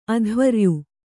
♪ adhvaryu